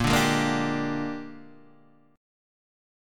Bb13 chord